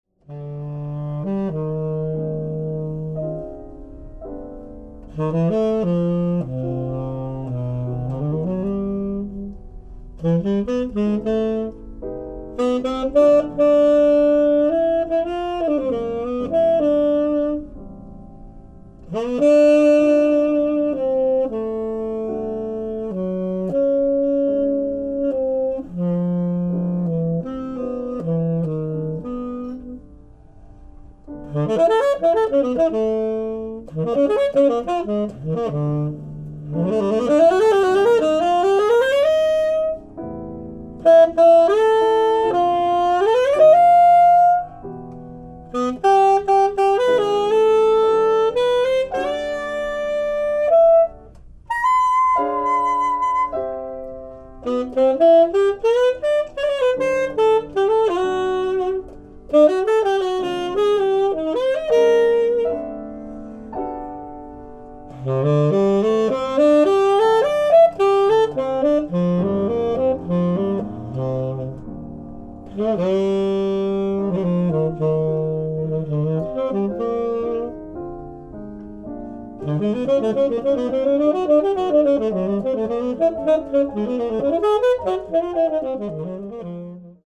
ディスク３＆４：ライブ・アット・メズロウ、ニューヨーク 04/05/2016
※試聴用に実際より音質を落としています。